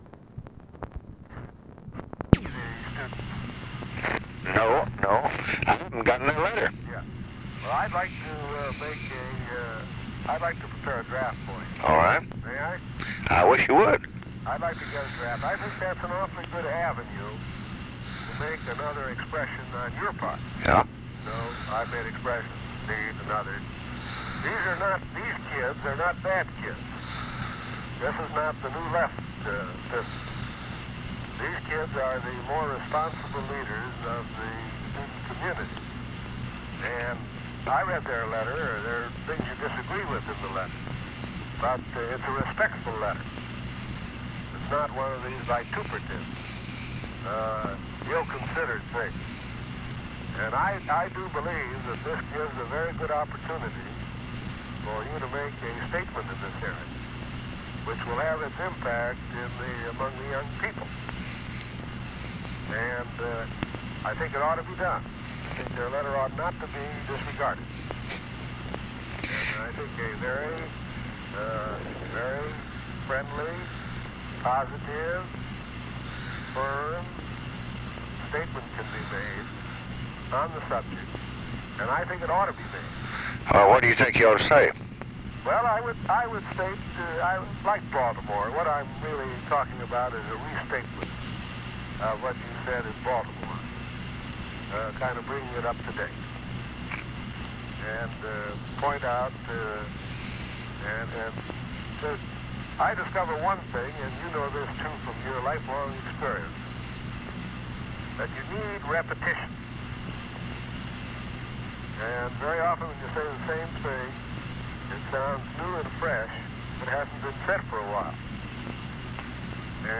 Conversation with ARTHUR GOLDBERG, December 31, 1966
Secret White House Tapes